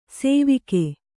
♪ sēvige